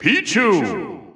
The announcer saying Pichu's name in English and Japanese releases of Super Smash Bros. Ultimate.
Pichu_English_Announcer_SSBU.wav